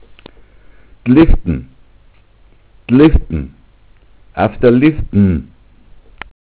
Mundart: 'd'lifdn' Aufgrund der römischen Nachbarflurnamen von Wintershof ist eine lat.